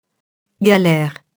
galère [galɛr]